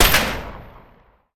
Wpn_pistolmauser_fire_2d.ogg